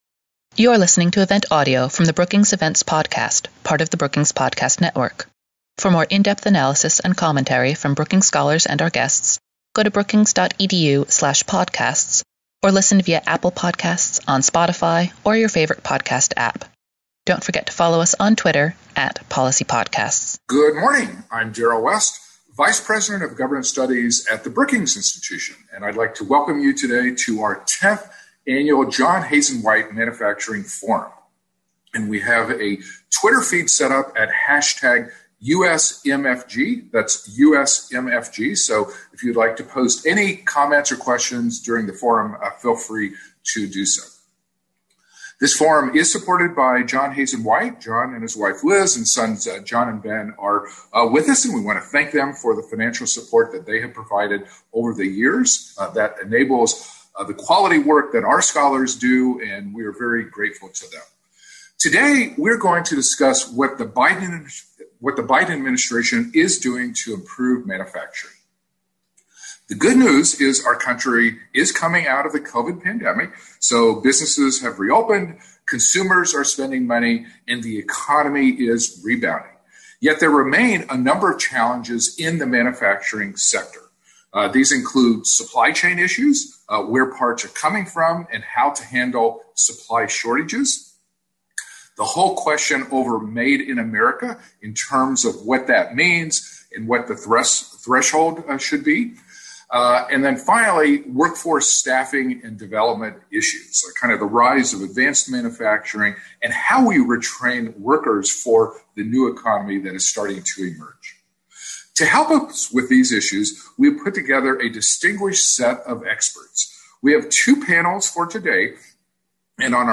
On July 15, Governance Studies at Brookings hosted a webinar to discuss the current manufacturing landscape and manufacturing initiatives proposed by the Biden-Harris administration. Speakers discussed challenges facing the U.S. manufacturing industry, opportunities to renew growth, and the future trajectory of the industry.